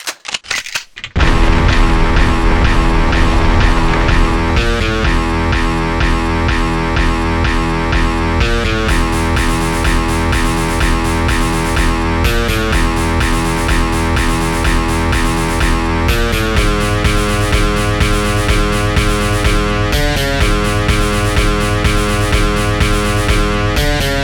2 channels
genericreload.wav m16_boltcatch.wav m3_pump.wav c4_explode1.wav
reload.mp3